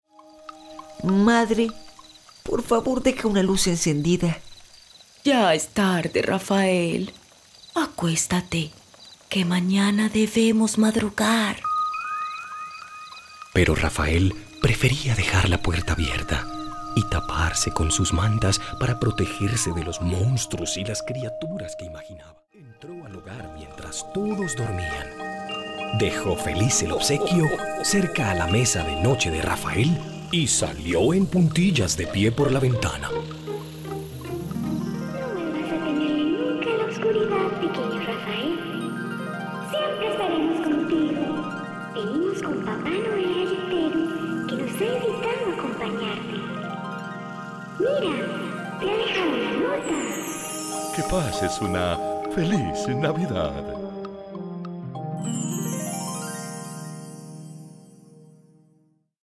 Fragmentos de audiolibros
“Cuentos de navidad”. Serie ambientada.